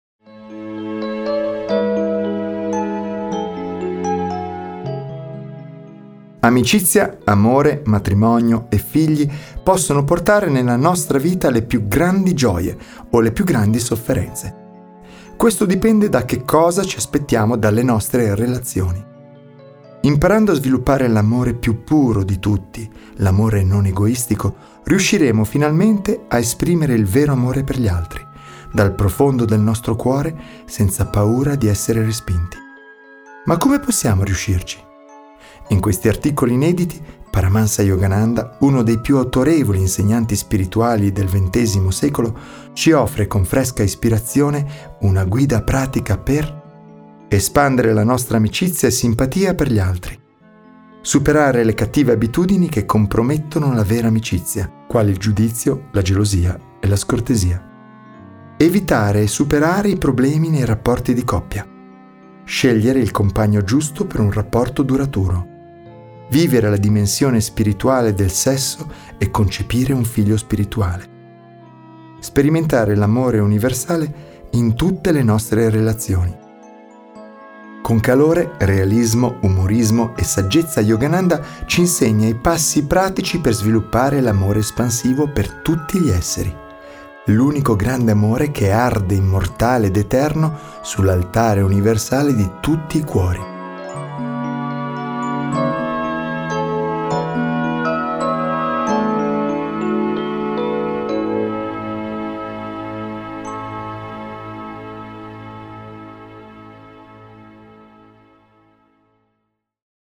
Audiolibri Saggezza